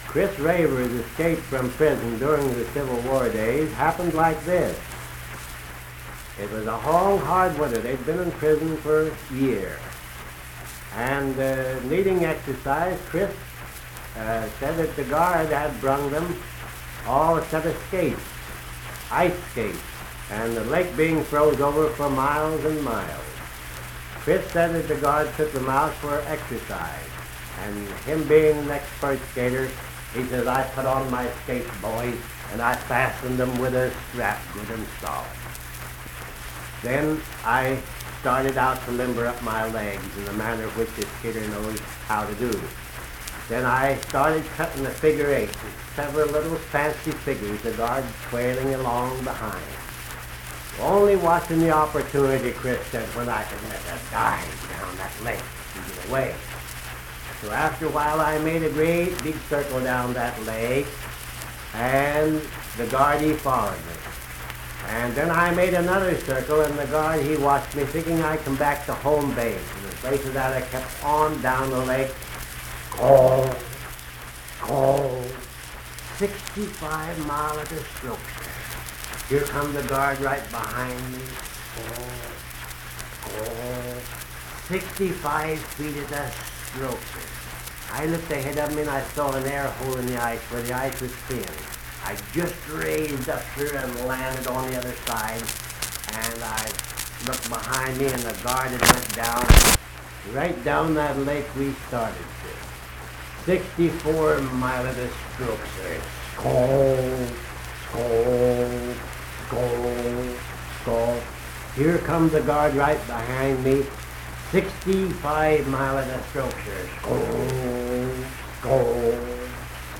Unaccompanied vocal music
Performed in Hundred, Wetzel County, WV.
Voice (sung)